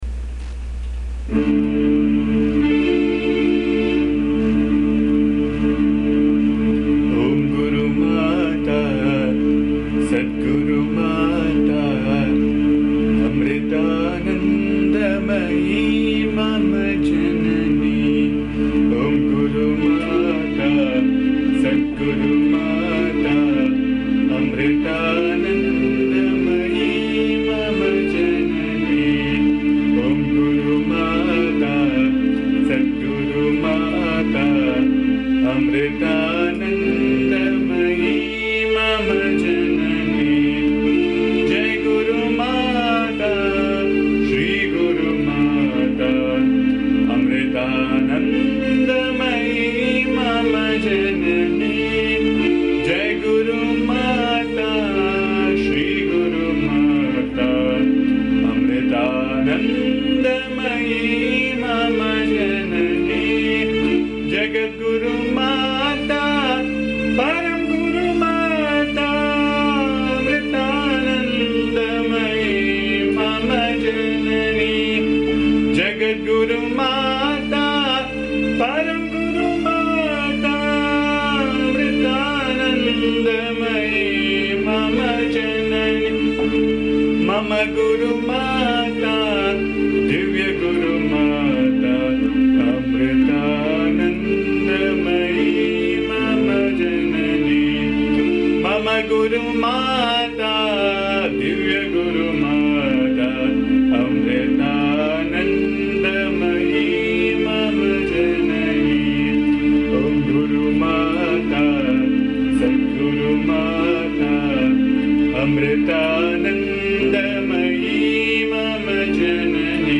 Please bear the noise, disturbance and awful singing as am not a singer.
AMMA's bhajan song